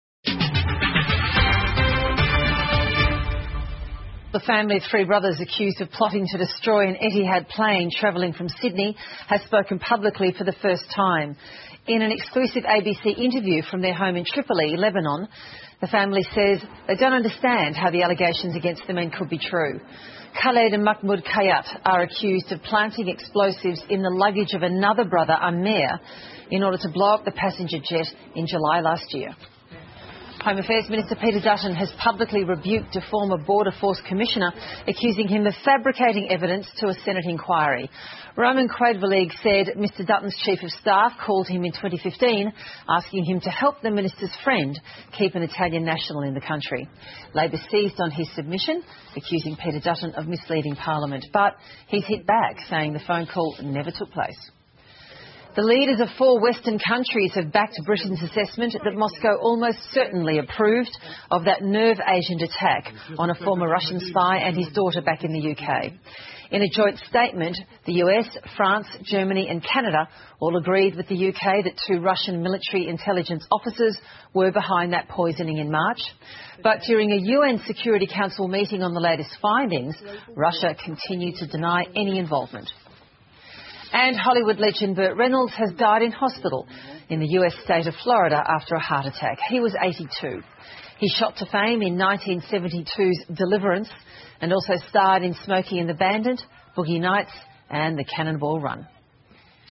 澳洲新闻 (ABC新闻快递) 俄罗斯驳斥英国“投毒门”表态 好莱坞老牌影星伯特·雷诺兹去世 听力文件下载—在线英语听力室